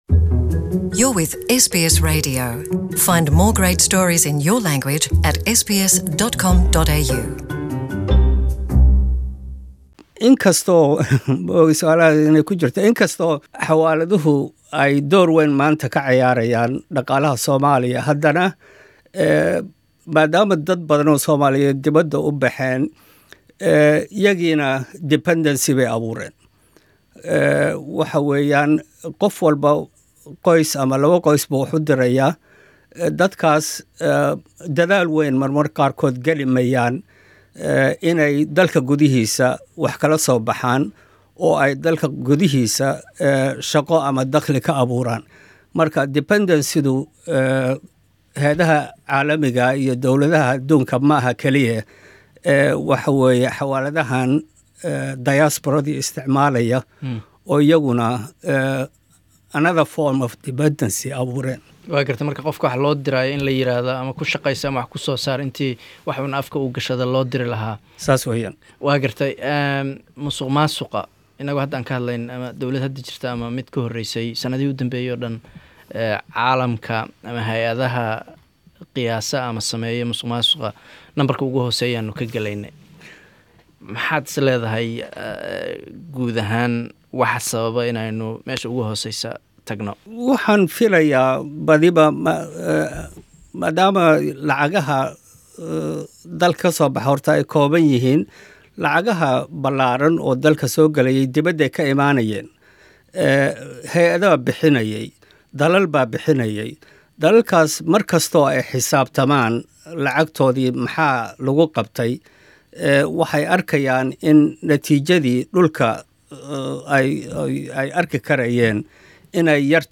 Interview with economist